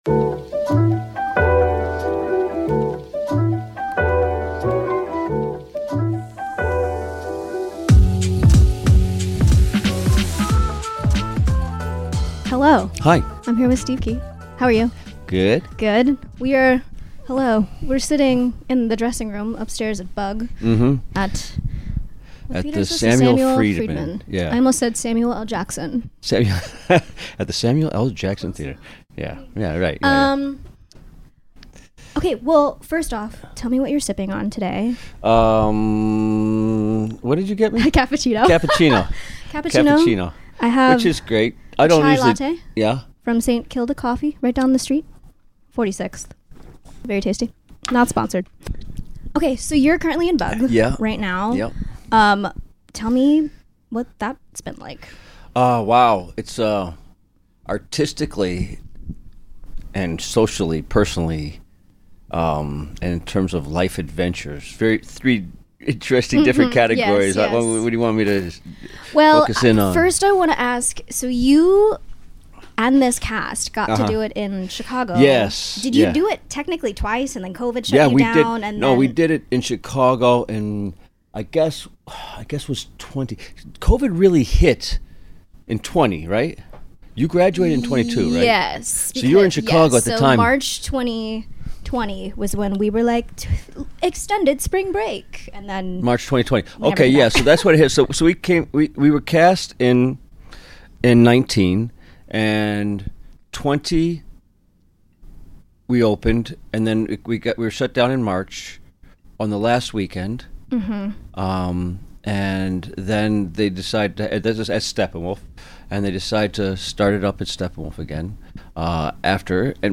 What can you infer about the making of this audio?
🎤 Shure SM58 with Focusrite